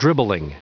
Prononciation du mot dribbling en anglais (fichier audio)
Prononciation du mot : dribbling